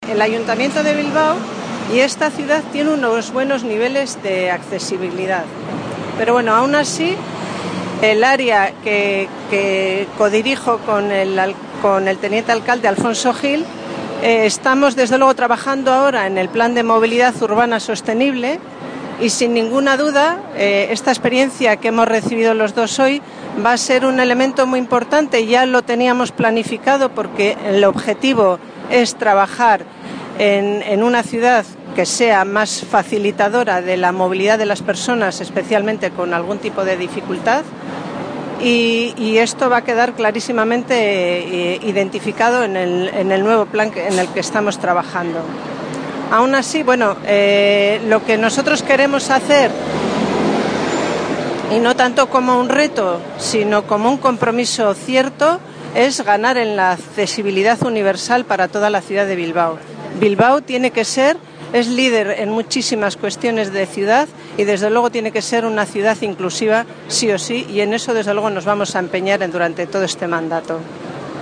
Su compañera, la concejal delegada adjunta del Área, Inés Ibáñez de Maeztu, hizo por otro lado un balance global de la experiencia y de las conclusiones que revela respecto a la accesibilidad objetiva de la ciudad de Bilbao y sus infraestructuras de transporte, cuya consecución